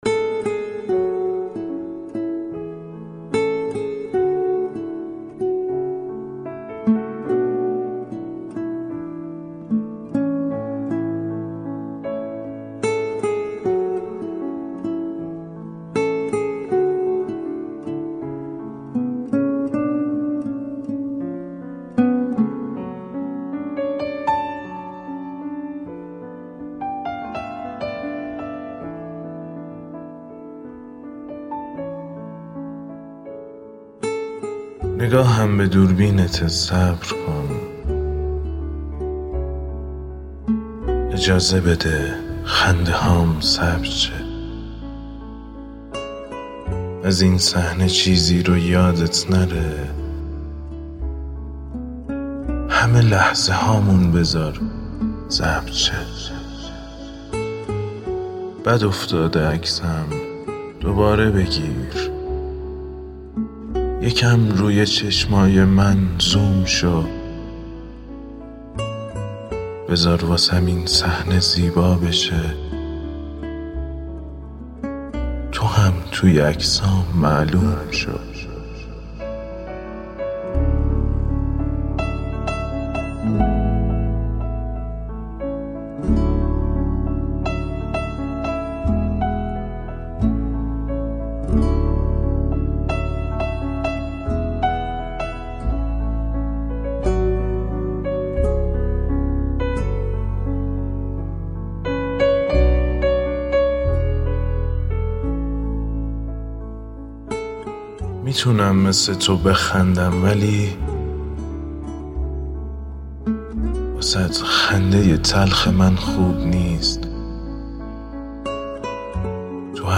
نفرات برتر چالش گروهی دکلمه دکلمافون – دوره ۶